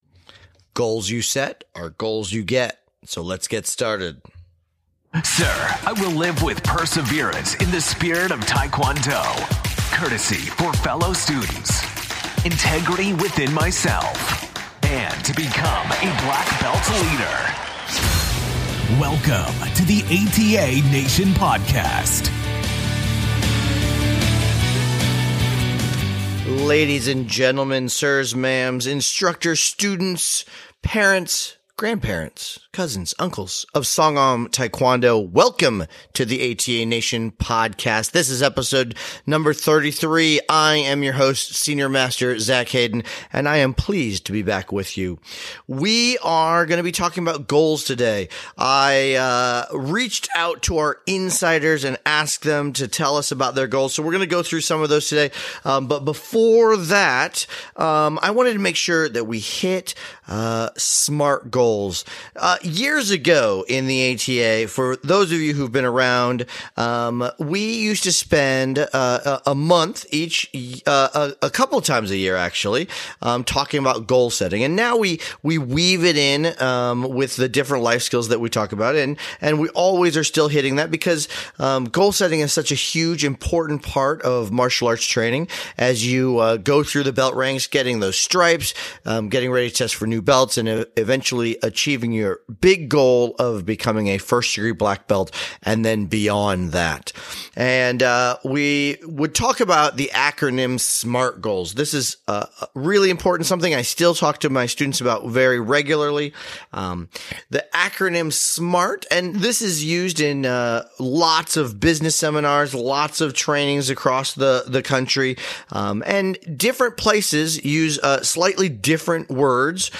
We're kicking off our November with a talk all about setting SMART goals! Not only do we include tips on how to set goals in today's episode but we also include tons of listener feedback and audio from YOU, ATA NATION!